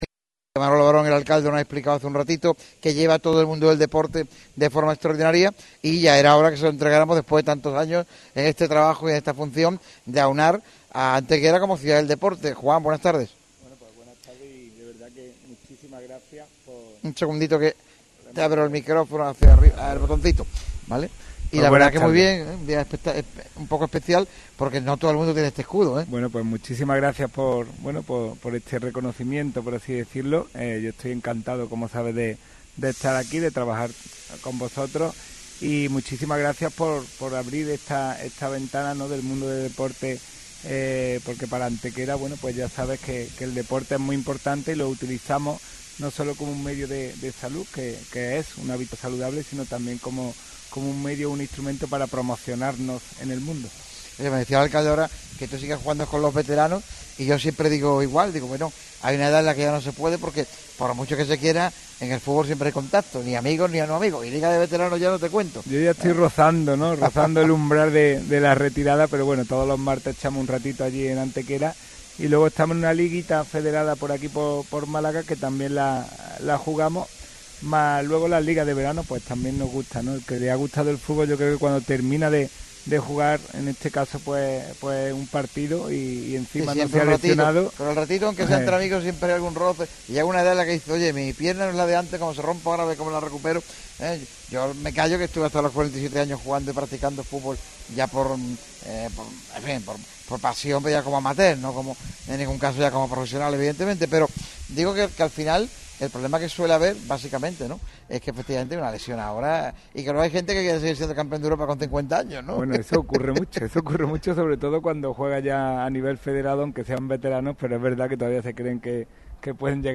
Juan Rosas, concejal de deportes del Ayuntamiento de Antequera pasó por el micrófono rojo de Radio MARCA Málaga en el programa especial de Cervezas Victoria sobre la ciudad del Torcal.